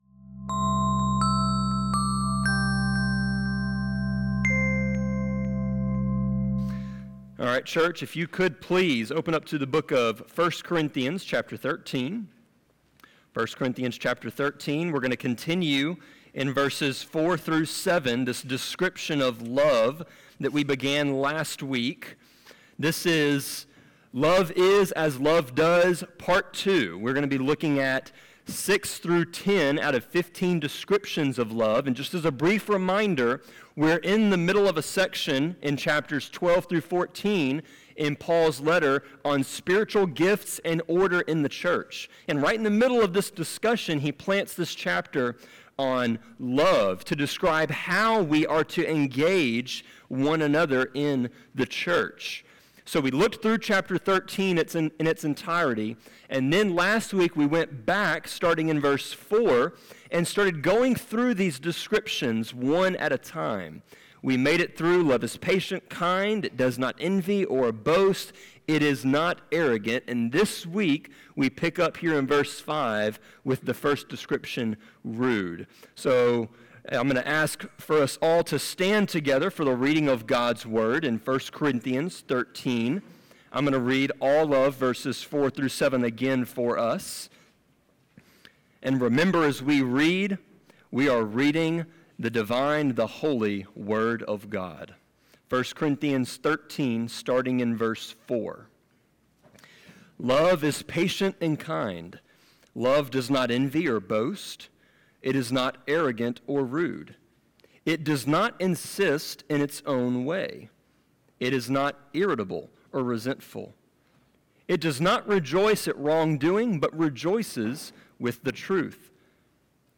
Sermon-23.8.20.m4a